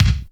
SINGLE HITS 0005.wav